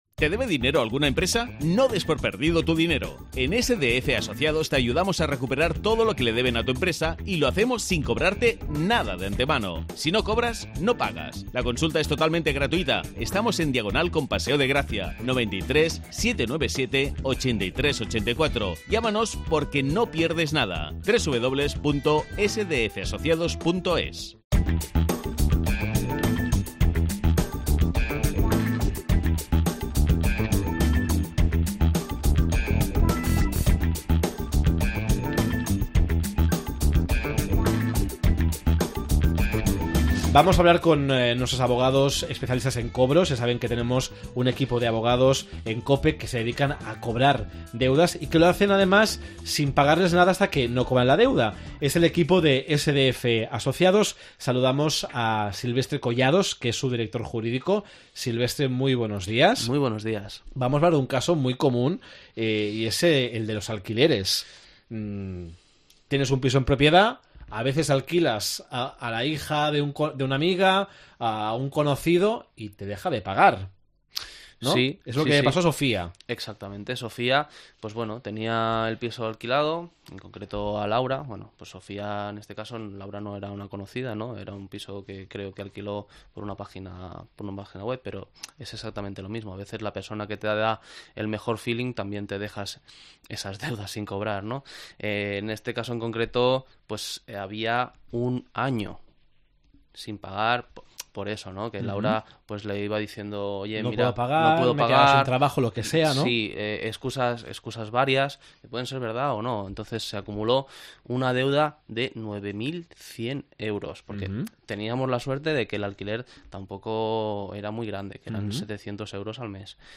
Duros a cuatre Duros a 4 ptas Com podem recuperar els diners que ens deuen? Entrevista